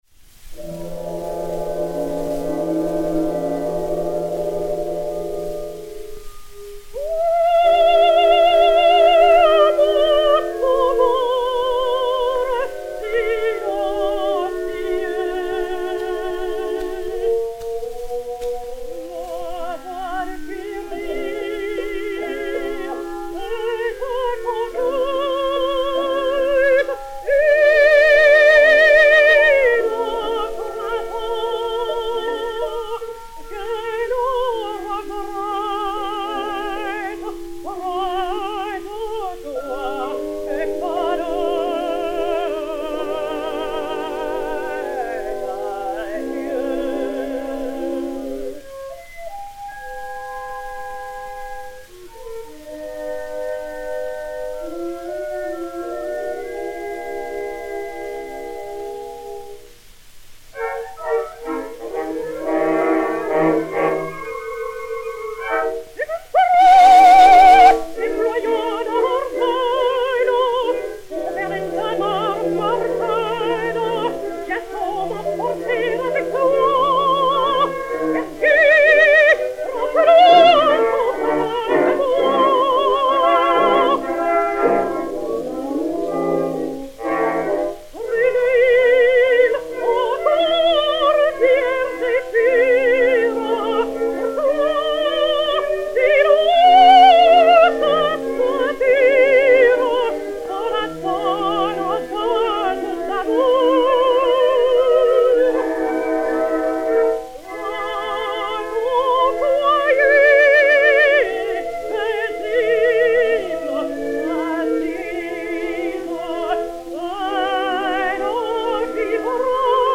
Elle chante trois langues, car j'ai oublié l'allemand ; sa voix, souple et moelleuse, se prête aux plus grands rôles lyriques ; son instinct dramatique, mûri par l'expérience, lui permet d'évoquer les figures les plus caractéristiques de la scène : l'avenir est à elle.
soprano de l'Opéra Orchestre
Disque Pour Gramophone 33764, mat. 15866u, et 33791, mat. 15915u, enr à Paris les 15 et 17 juin 1910